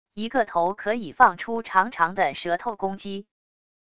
一个头可以放出长长的舌头攻击wav音频